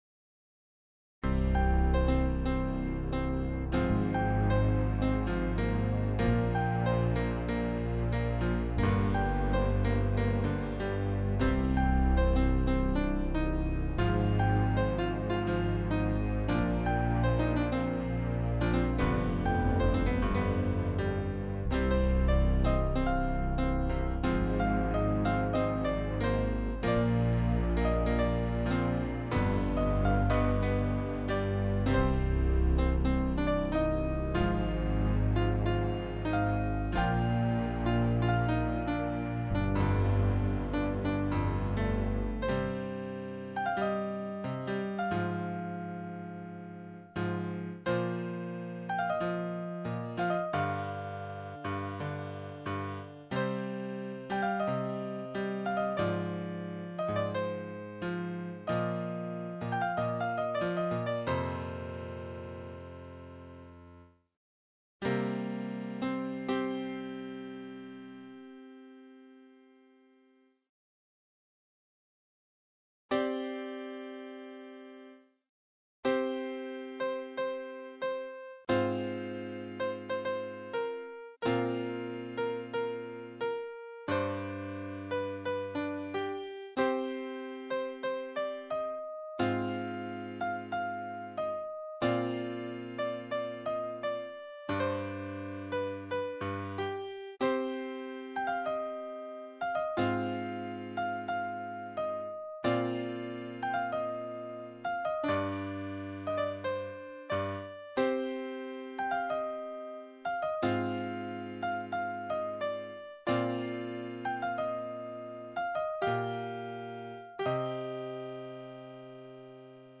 Melody piano piece